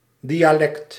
Ääntäminen
Synonyymit slang Ääntäminen US Tuntematon aksentti: IPA : /ˈdaɪ.ə.ˌlɛkt/ Lyhenteet ja supistumat (kielioppi) dial.